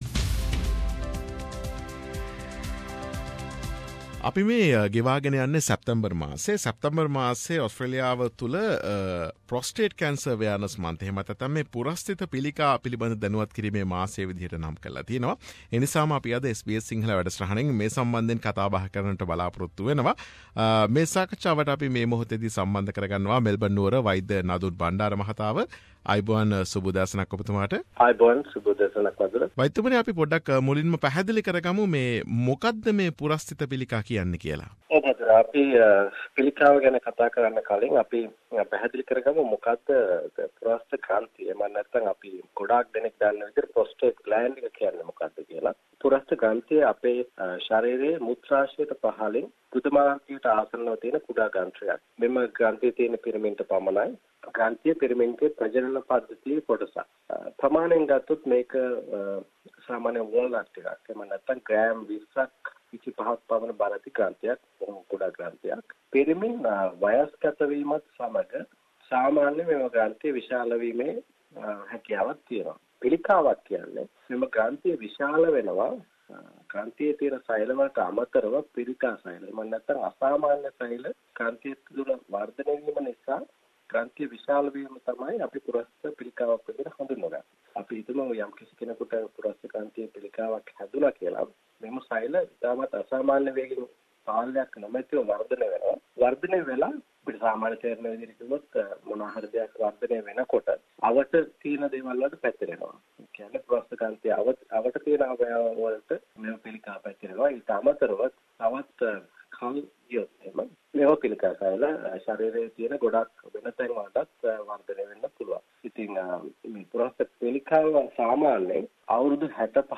වෛද්‍ය සාකච්ඡාව